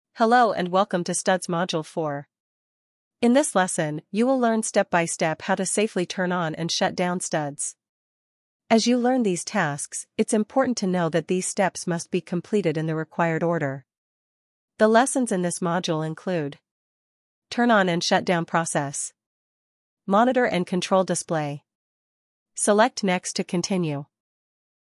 Text-to-Speech Audio for Narration
We use AI-generated text-to-speech audio to narrate digital learning.